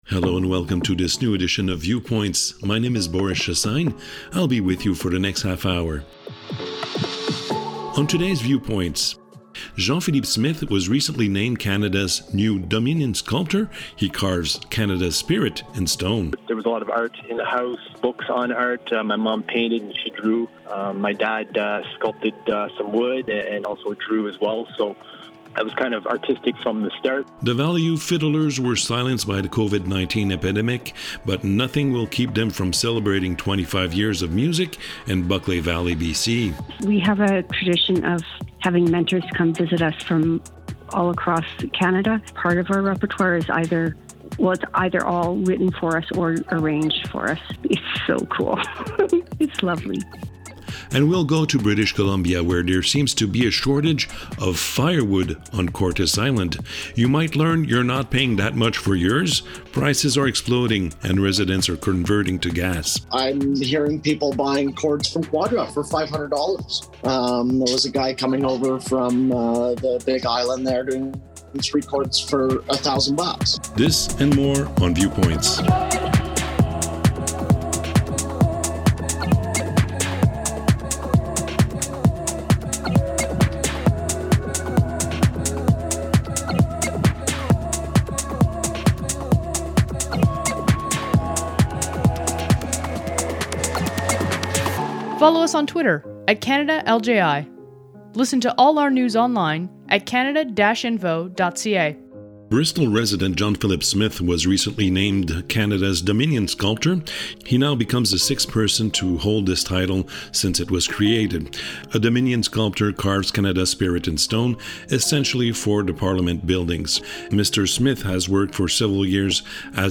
The Community Radio Fund of Canada produces a new weekly radio series called Viewpoints, a 30 minute news magazine aired on 30 radio stations across Canada. Viewpoints provides an overview of what’s happening across the country, thanks to some 20 radio correspondents working for the Local Journalism Initiative in British Columbia, Alberta, Ontario, Québec, New Brunswick and Nova Scotia.